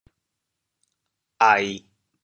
潮州发音
ai1.mp3